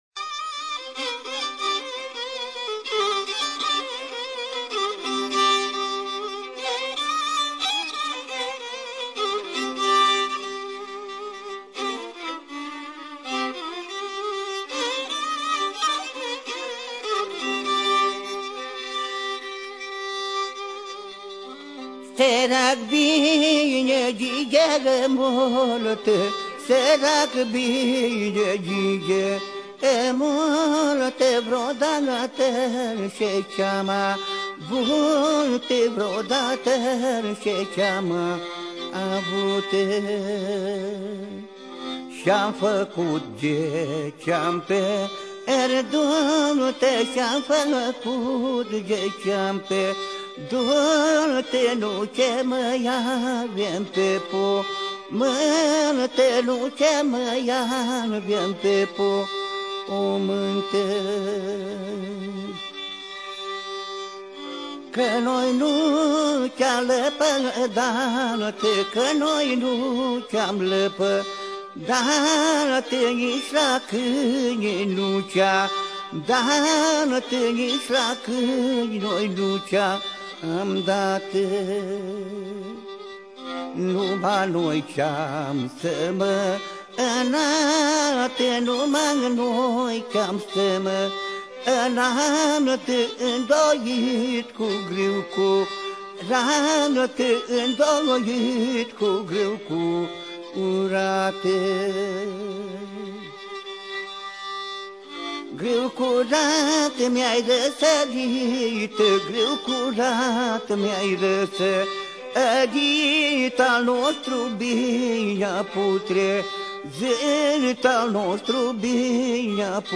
VLAŠKI LAUTAR